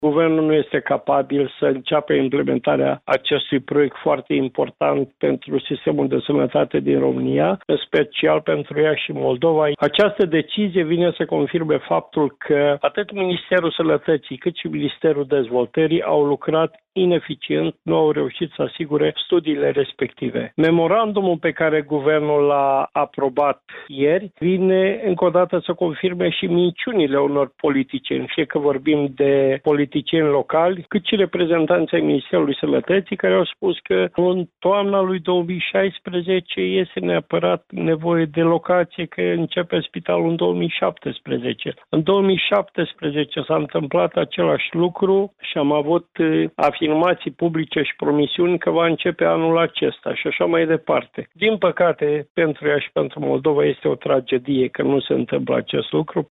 Preşedintele filialei ieşene a PMP, deputatul Petru Movilă, a criticat vineri, într-o conferinţă de presă, decizia actualei guvernări de a amâna construcţia Spitalelor Regionale de Urgenţe.